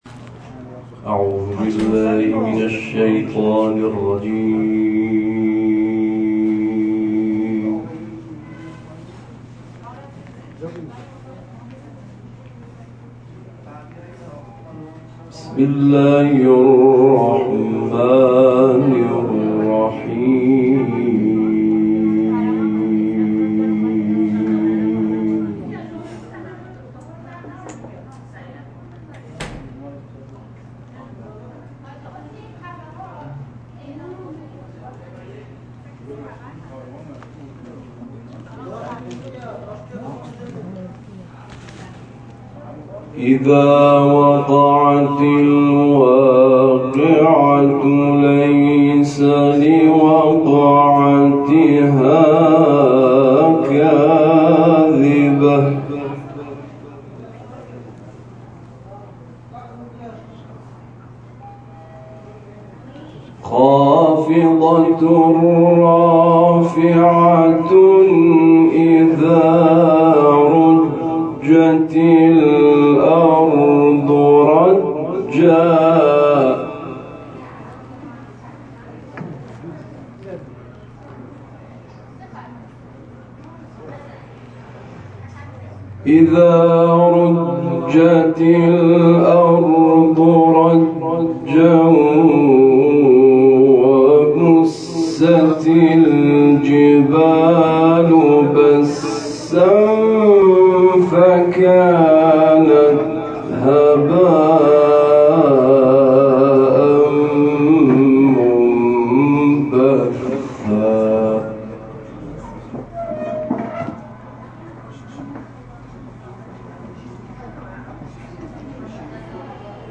تلاوت در سرزمین وحی و زیارت نیابتی از شهید حججی